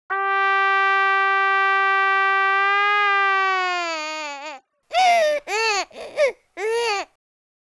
Here is an example of a trumpet morphed in a crying baby (not sure why you'd want to do that).
All sound morphs and syntheses presented here were created using the open source Loris software for sound analysis, synthesis, and manipulation.
trumpetcry.wav